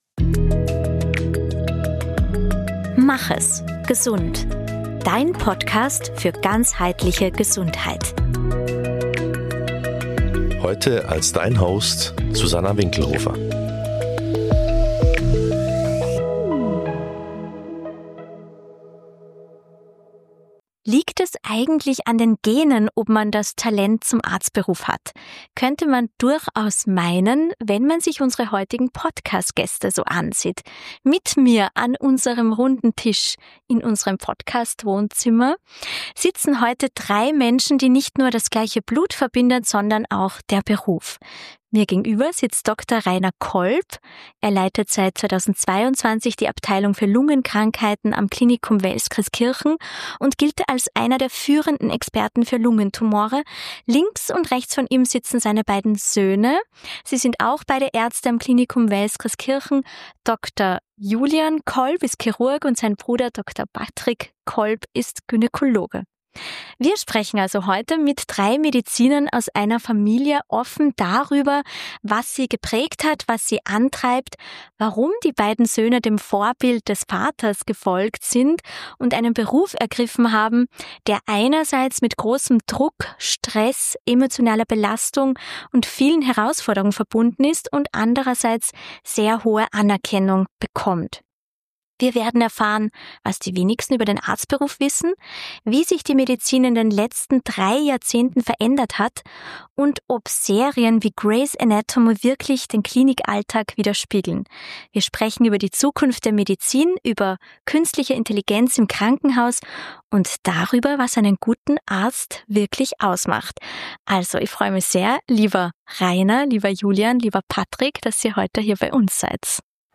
Ein Gespräch über Leidenschaft, Zweifel und den Wandel des Berufes.